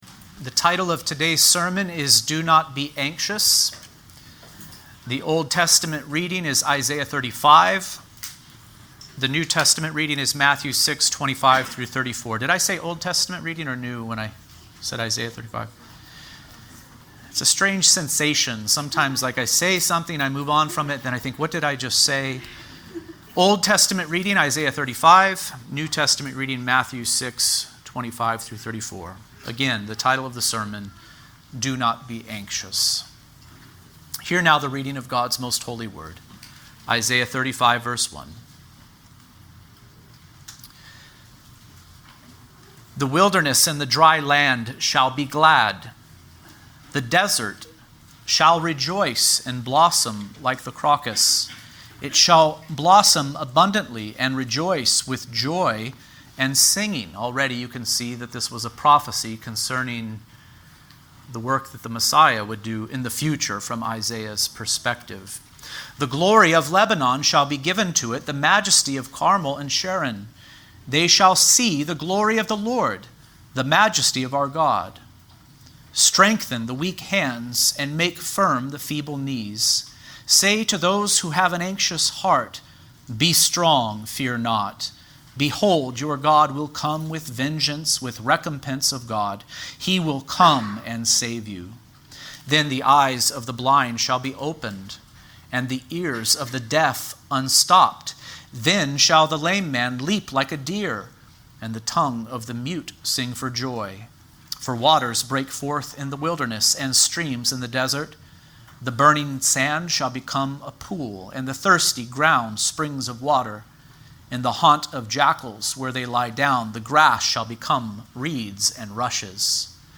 Do Not Be Anxious | SermonAudio Broadcaster is Live View the Live Stream Share this sermon Disabled by adblocker Copy URL Copied!